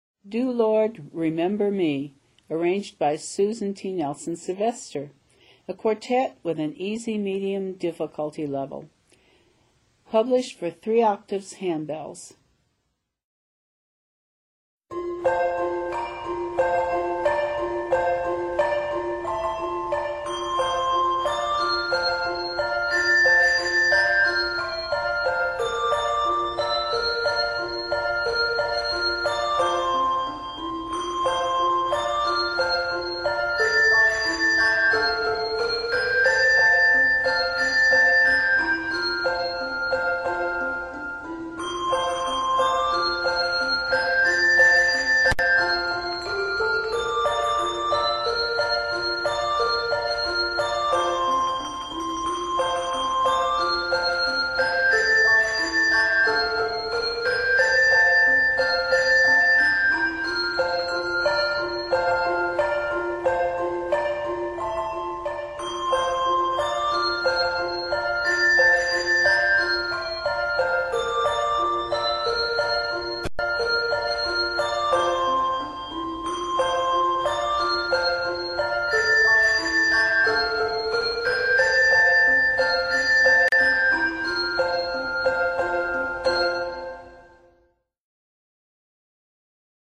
Set in F Major, this piece is 39 measures.